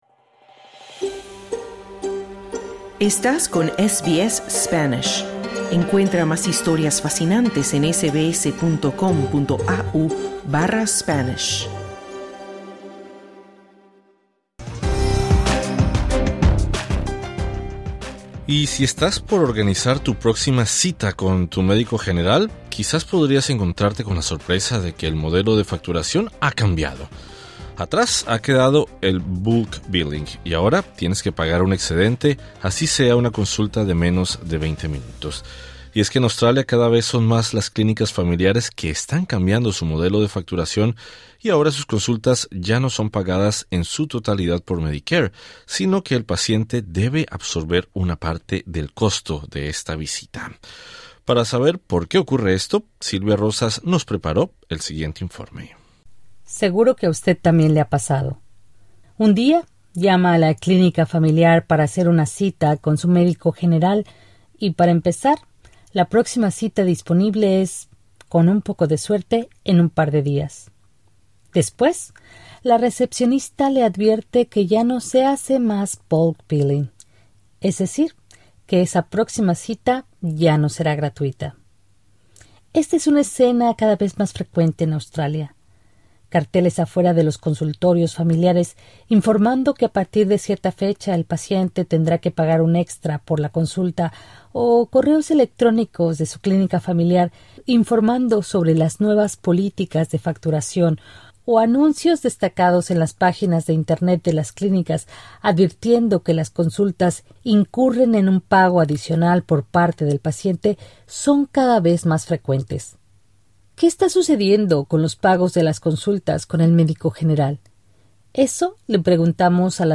Si has visitado recientemente a tu médico general, quizá te haya tocado pagar un excedente por esa consulta que antes era costeada por Medicare en su totalidad; y es que cada vez son más las clínicas familiares que están cambiando su sistema de facturación. SBS Spanish conversó con dos médicas de cabecera de nuestra comunidad para saber cuáles son los motivos de estos ajustes.